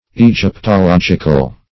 Definition of egyptological.
Search Result for " egyptological" : The Collaborative International Dictionary of English v.0.48: Egyptological \E*gyp`to*log"ic*al\, a. Of, pertaining to, or devoted to, Egyptology.